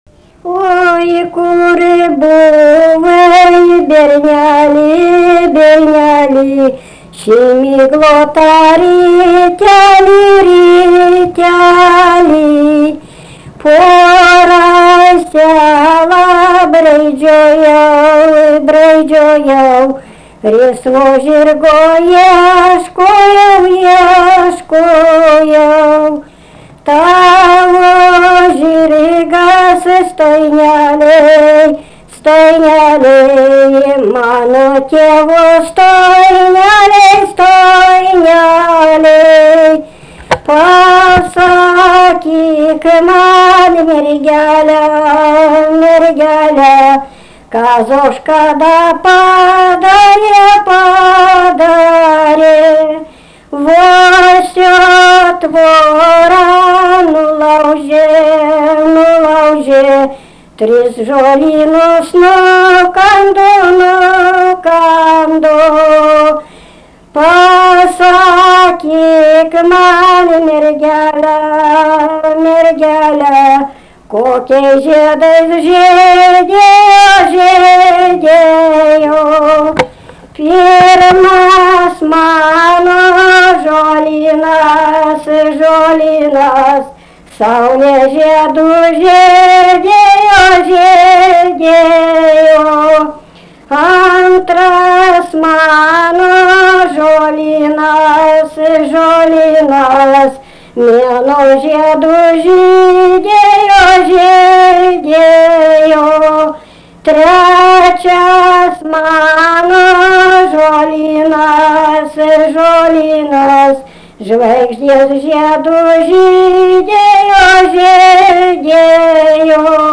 vokalinis
LMTA Mokslo centro muzikinio folkloro archyvas